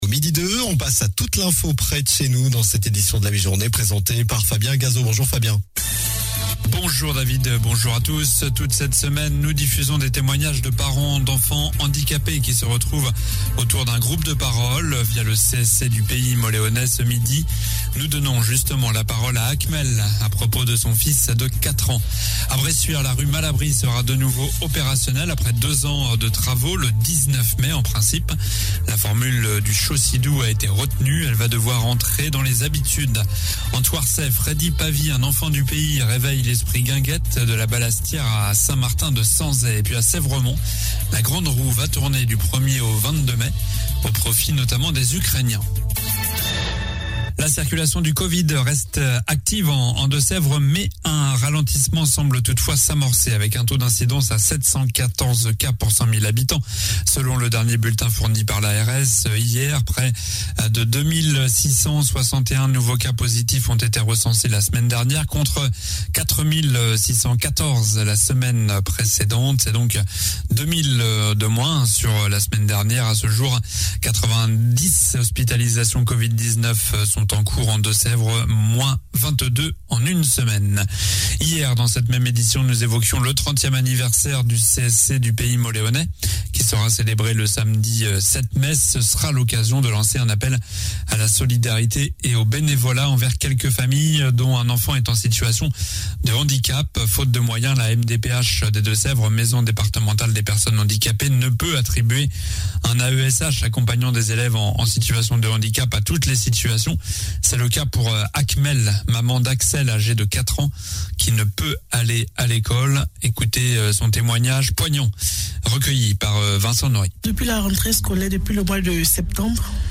Journal du mercredi 27 avril (midi)